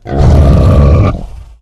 boar_aggressive_0.ogg